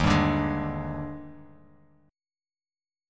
EbM11 Chord
Listen to EbM11 strummed